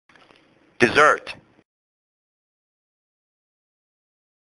老美都是這麼唸！
這才是道地的美式發音喔！
“ss”發音是/ z /，相當特殊。重音在第二音節，不是第一音節。